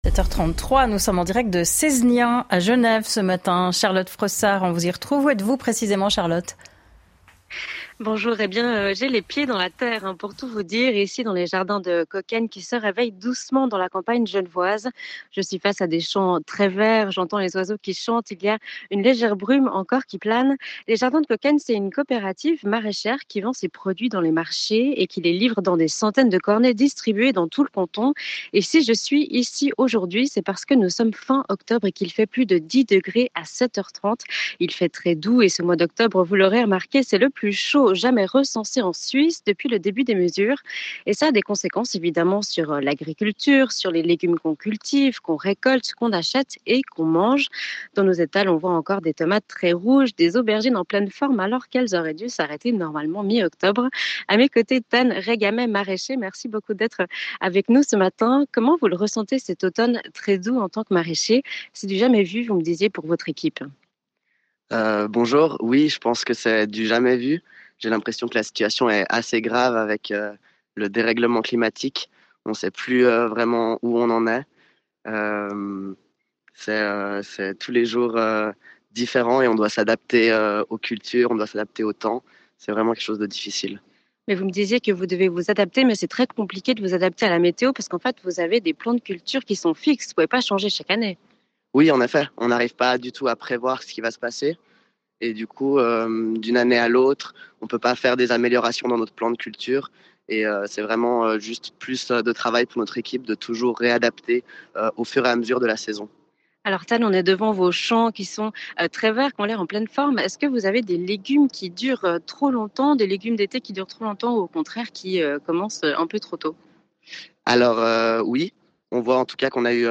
2 minutes d'interview en direct des jardins de Sézegnin!